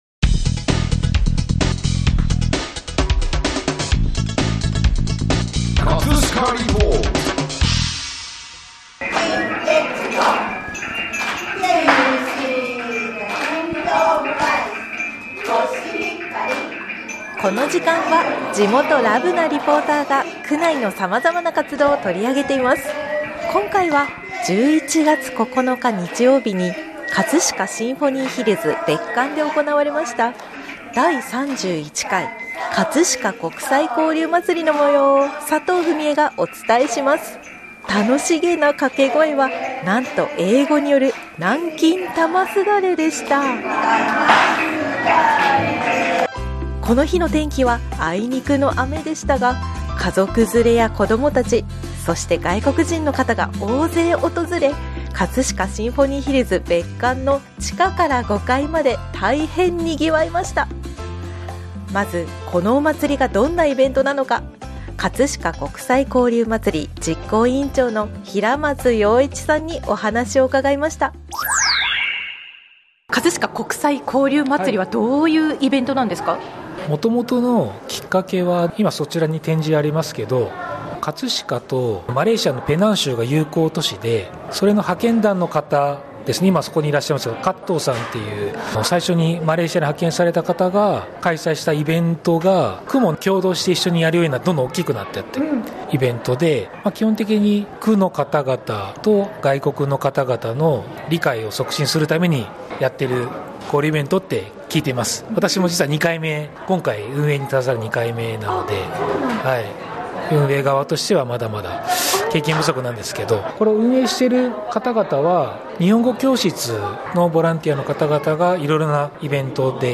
【葛飾リポート】 葛飾リポートでは、区内の様々な活動を取り上げています。